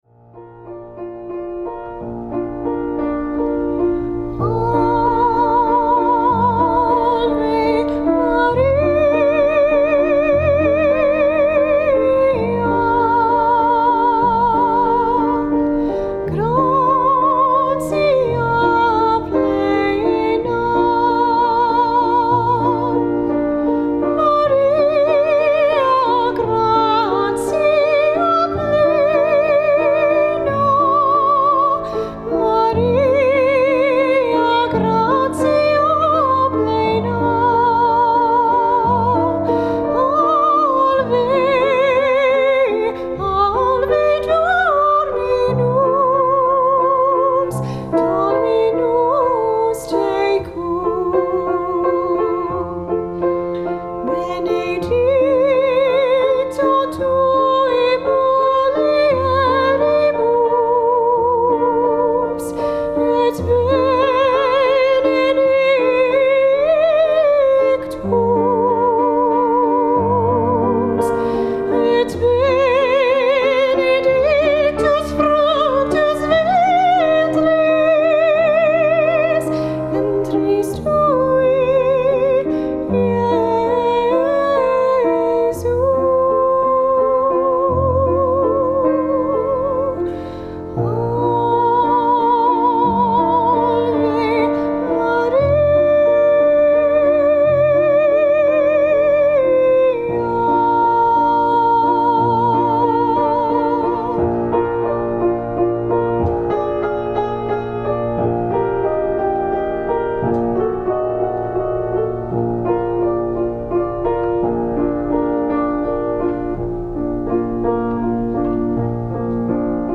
In Studio
vocal
piano